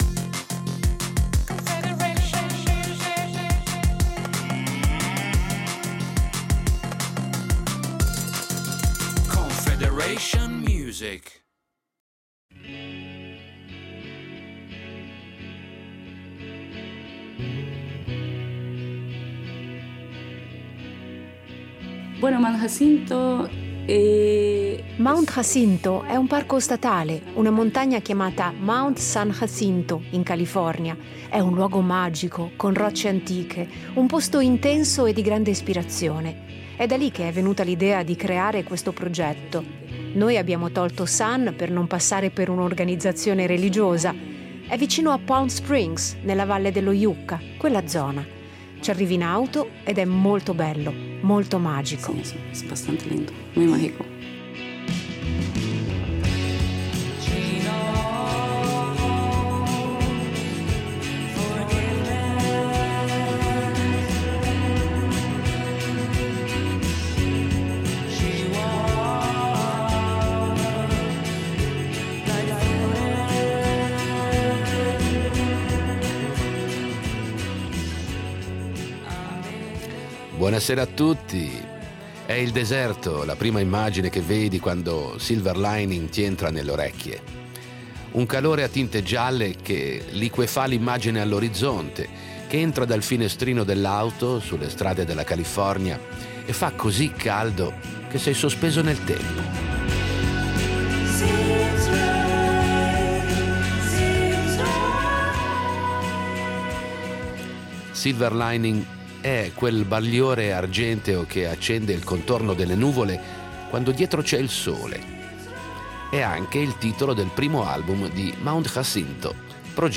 Musica rock